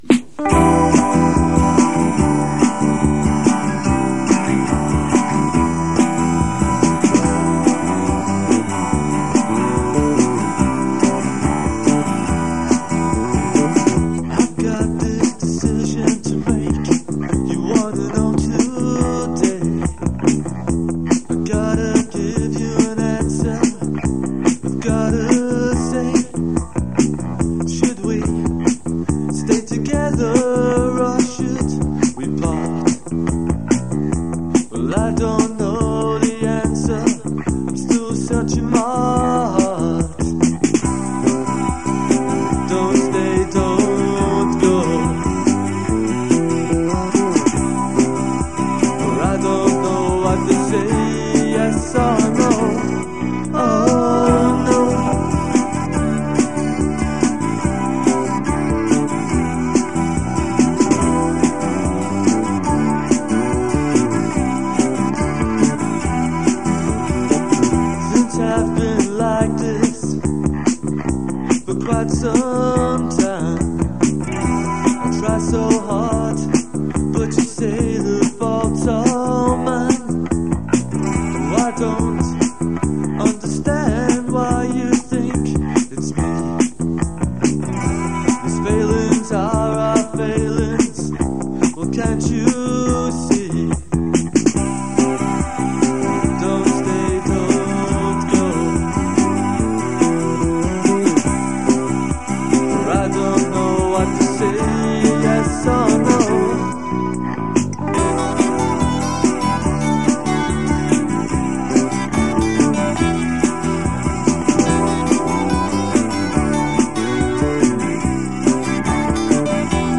He played guitar and drums.